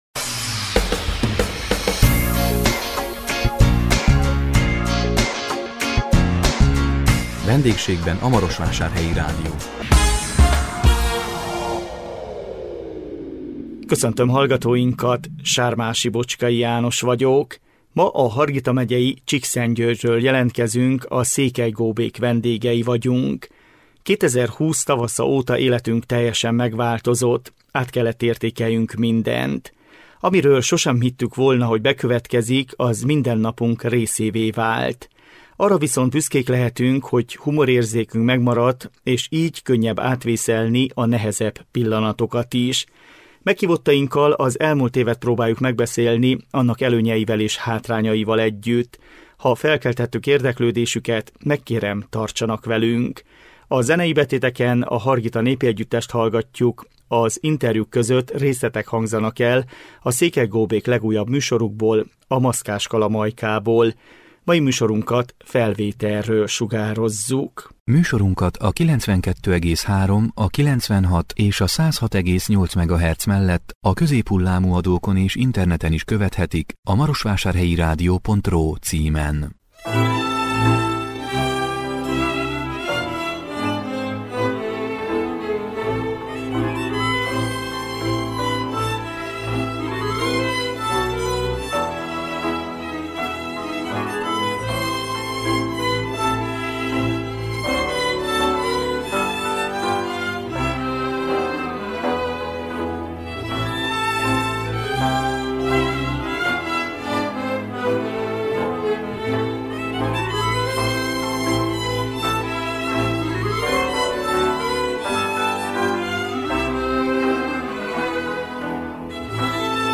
A 2021 június 3-án jelentkező VENDÉGSÉGBEN A MAROSVÁSÁRHELYI RÁDIÓ című műsorunkban a Hargita megyei Csíkszentgyörgyről jelentkezünk, a Székely Góbék vendégei voltunk. 2020 tavasza óta életünk teljesen megváltozott, át kellett értékeljünk mindent.